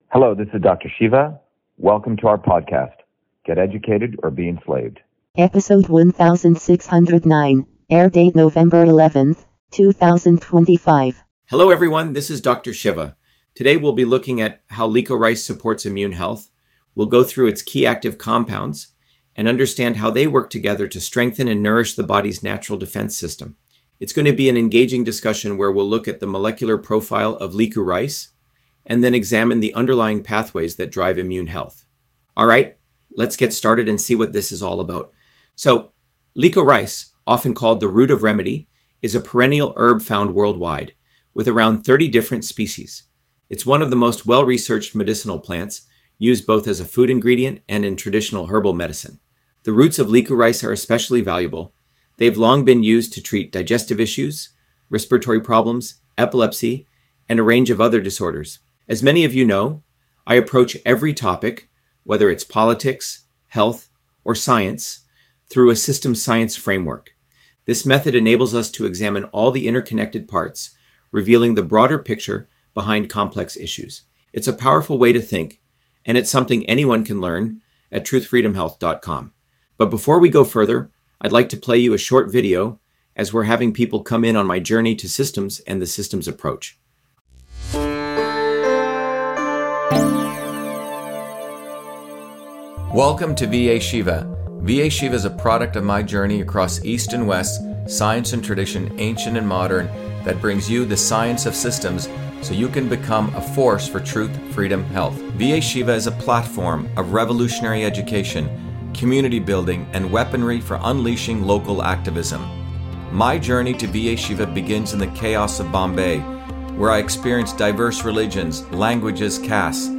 In this interview, Dr.SHIVA Ayyadurai, MIT PhD, Inventor of Email, Scientist, Engineer and Candidate for President, Talks about Licorice on Immune Health: A Whole Systems Approach